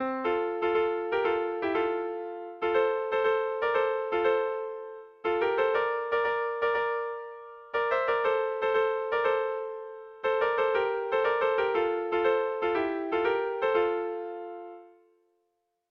Sentimenduzkoa
3.puntua errepikatu egin ohi da hiru aldiz.
Lau puntuko berdina, 8 silabaz
ABDEFG